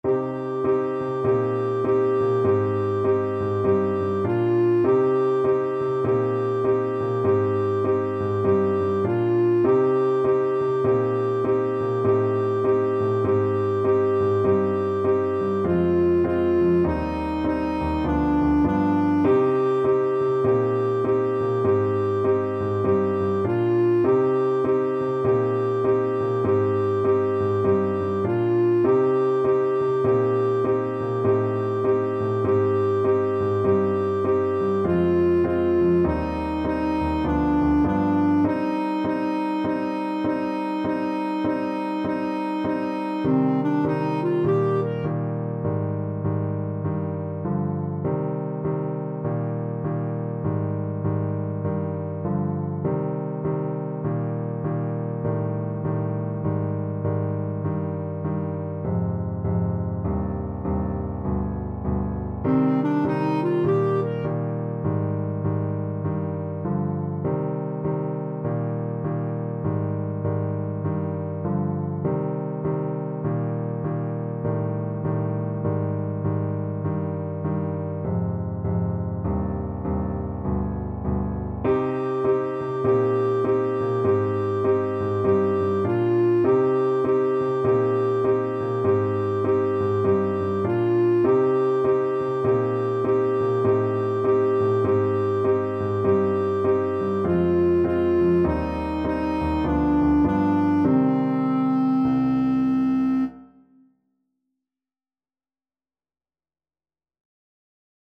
4/4 (View more 4/4 Music)
C5-Bb5
Jazz (View more Jazz Clarinet Music)
Rock and pop (View more Rock and pop Clarinet Music)